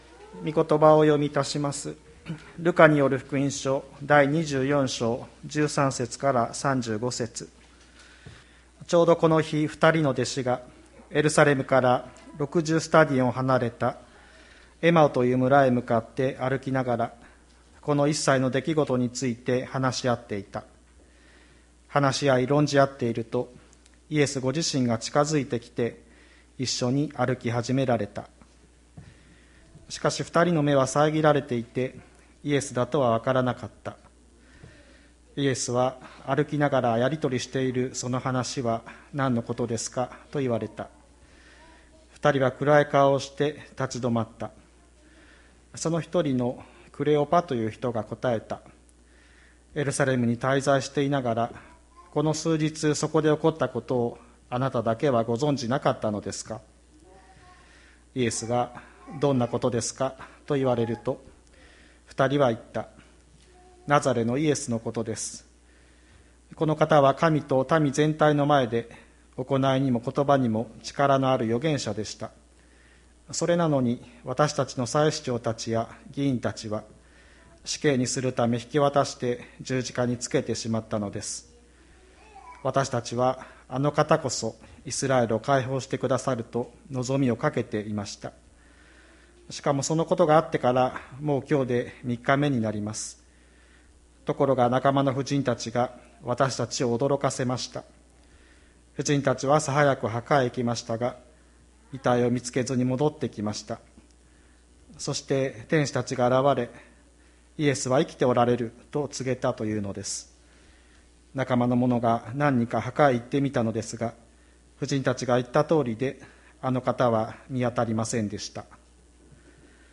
千里山教会 2021年04月04日の礼拝メッセージ。